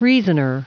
Prononciation du mot reasoner en anglais (fichier audio)
Prononciation du mot : reasoner